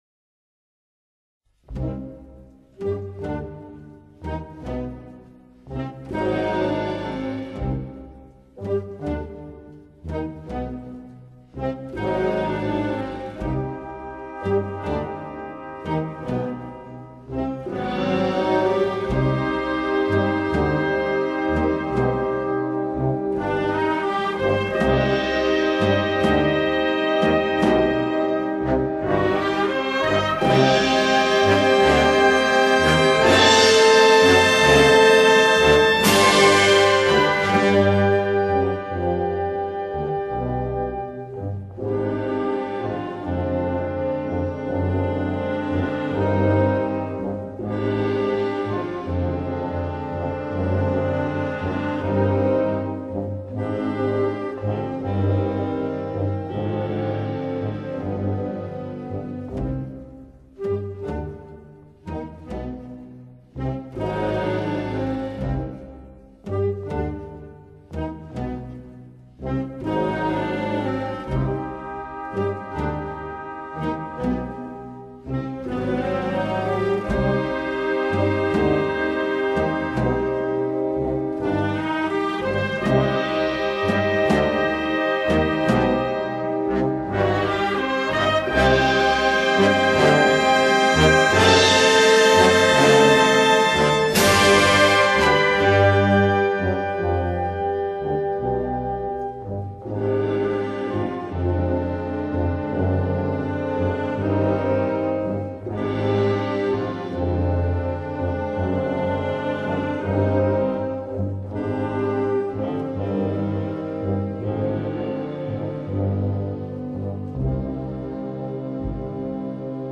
Se distinguen composiciones de música procesional y música de capilla, siendo tanto unas como otras, de gran reconocimiento en la Semana Santa de Sevilla, y fuera de la provincia.
Destacan sobremanera dos magníficas marchas procesionales debidas a la pluma de N.H.D. Antonio Pantión: “Jesús de las Penas” (1943) y “Tus Dolores son mis penas” (1970).